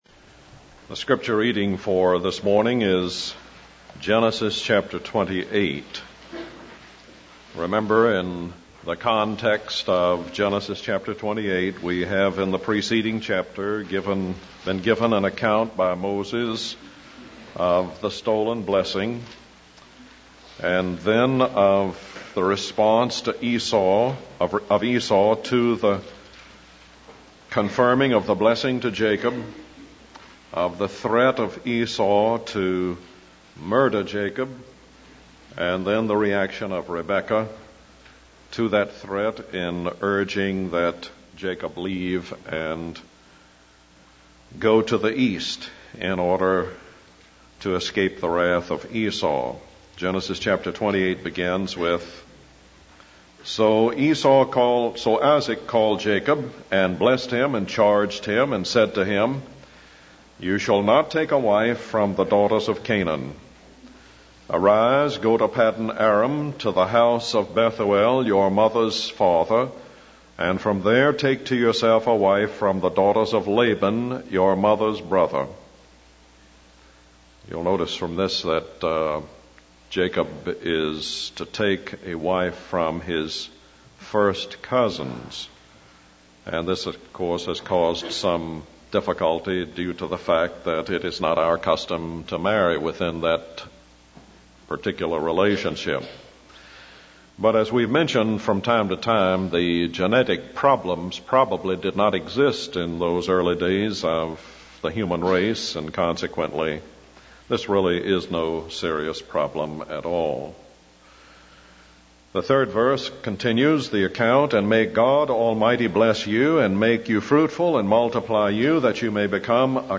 In this sermon, the preacher discusses the encounter between Jacob and God.